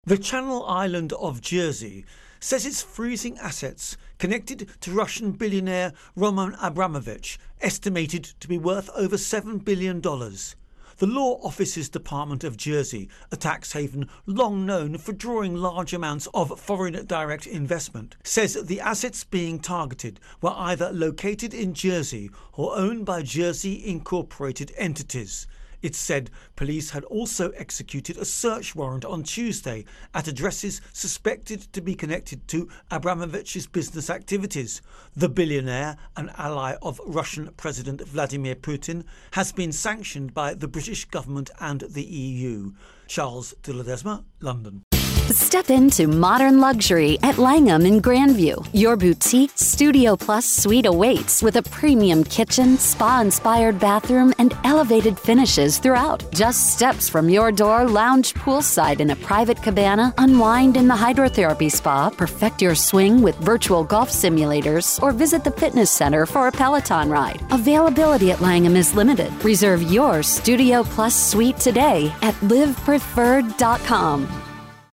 Russia Ukraine War Abramovich Intro and Voicer